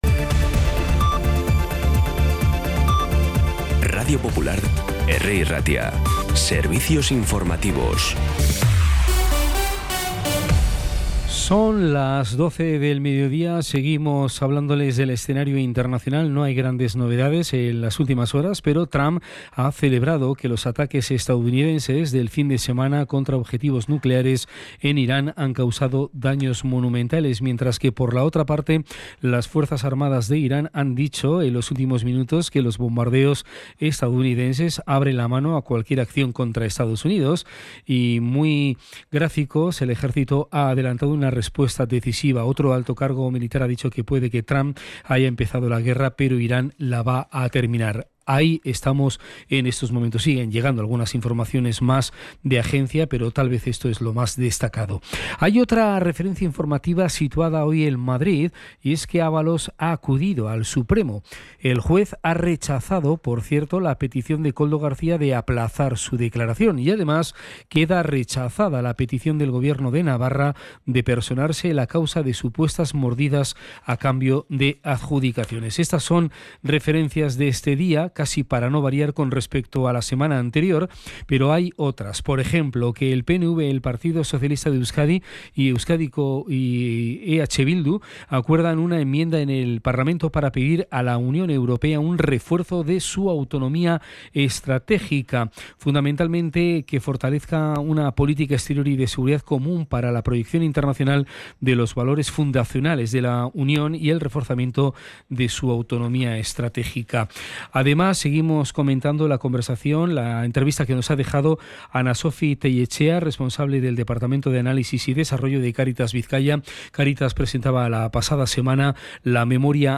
Información y actualidad desde las 12h de la mañana